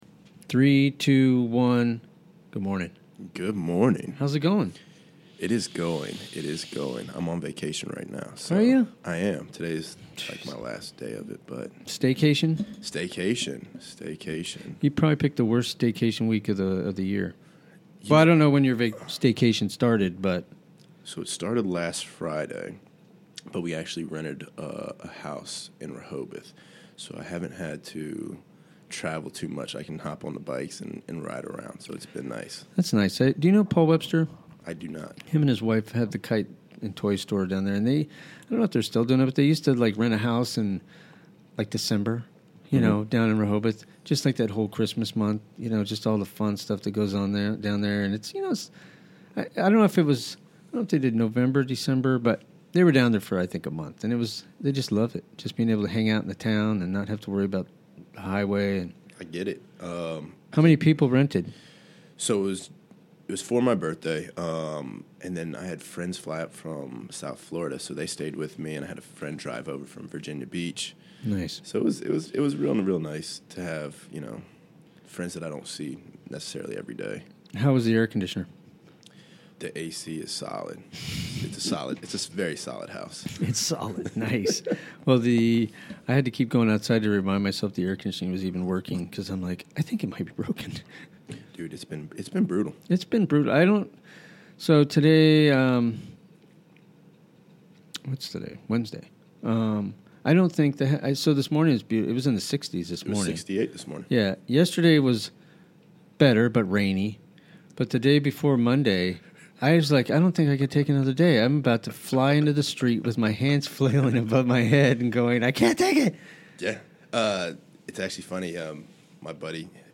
Chatting with local folks.